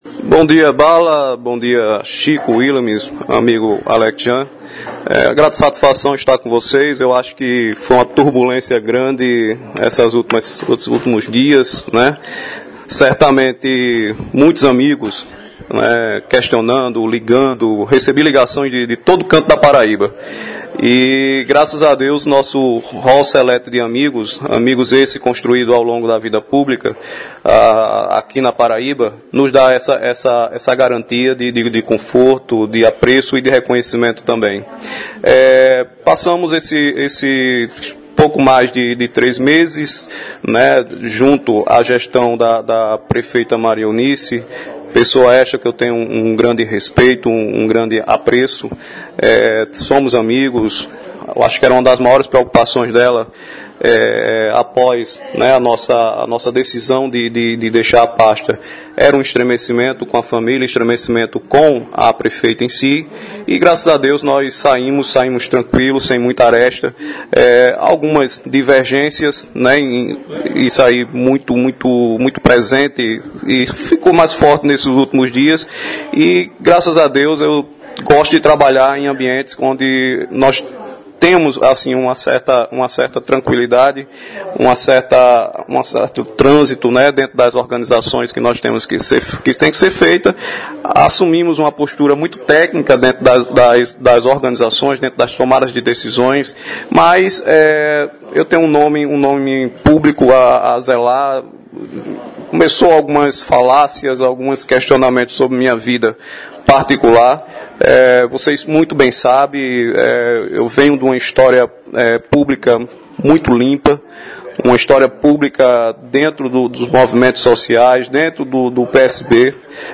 Após polêmicas e acusações, o pedagogo Gerailton Santos, ex-secretário de Educação e Cultura do governo da prefeita Maria Eunice (PSB) de Mamanguape, concedeu entrevista ao programa Rádio Repórter da Correio do Vale FM nesta quarta-feira (12).